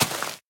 grass4.mp3